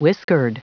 Prononciation du mot whiskered en anglais (fichier audio)
Prononciation du mot : whiskered